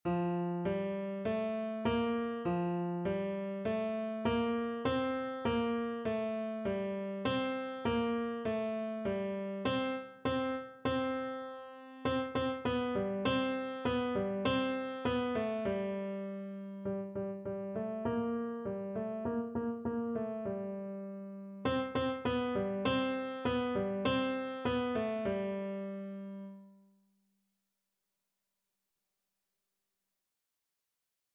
4/4 (View more 4/4 Music)
Piano  (View more Beginners Piano Music)
Classical (View more Classical Piano Music)